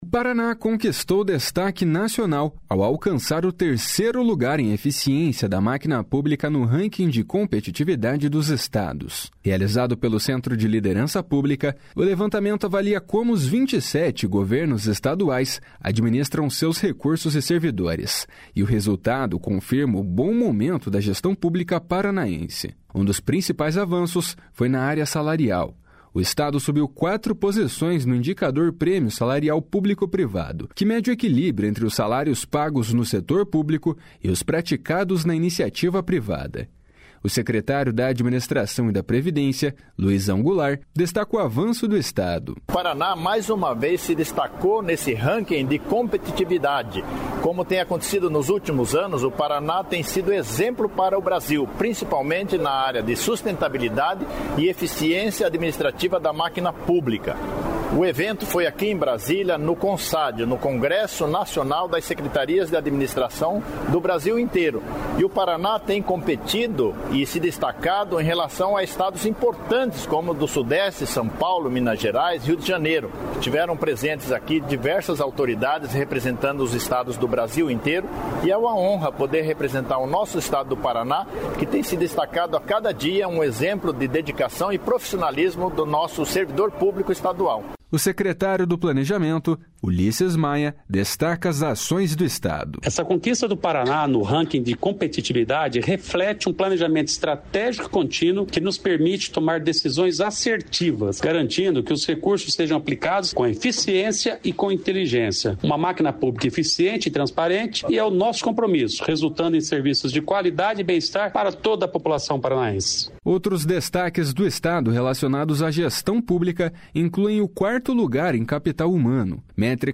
O secretário da Administração e da Previdência, Luizão Goulart, destaca o avanço do Estado.
O secretário do Planejamento, Ulisses Maia, destaca as ações do Estado.